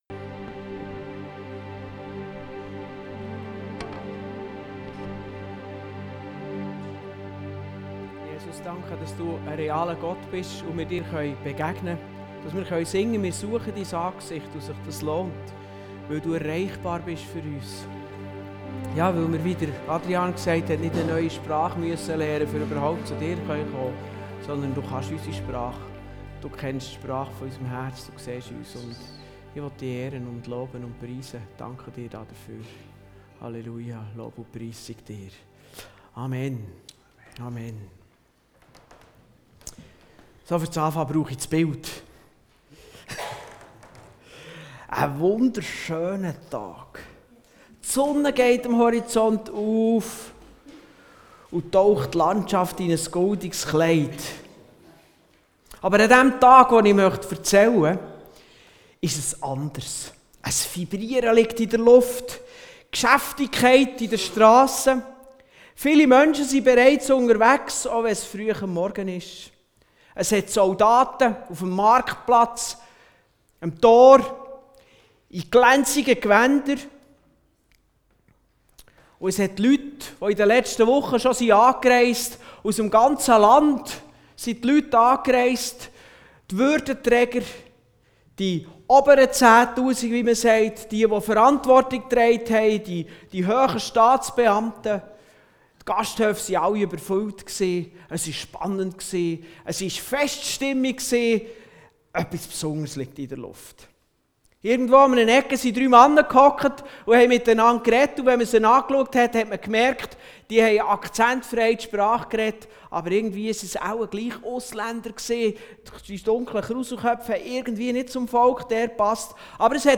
Serie: Rezepte für Bergversetzer Dienstart: Gottesdienst Dateien zum Herunterladen Notizen Themen